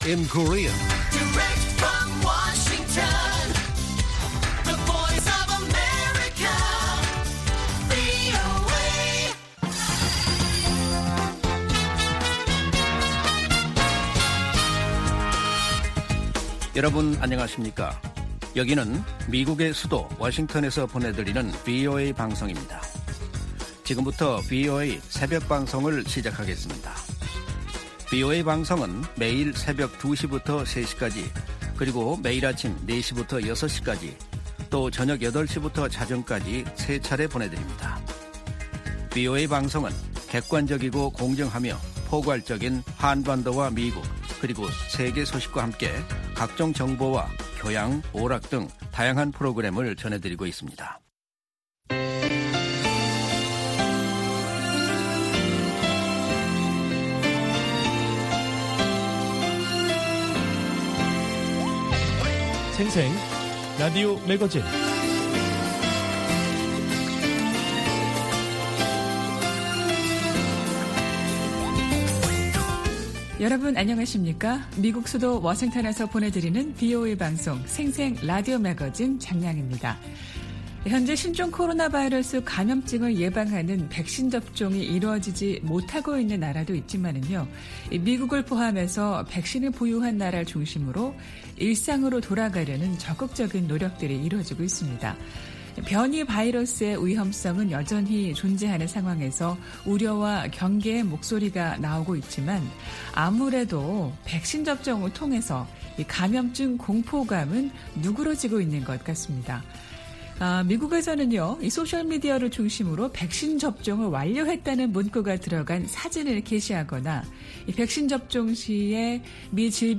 VOA 한국어 방송의 일요일 새벽 방송입니다.